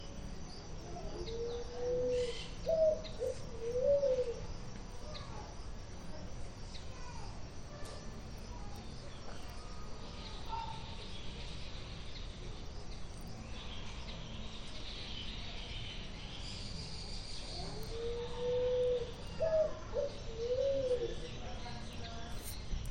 Paloma Picazuró (Patagioenas picazuro)
Nombre en inglés: Picazuro Pigeon
Localidad o área protegida: Gran Buenos Aires Norte
Condición: Silvestre
Certeza: Observada, Vocalización Grabada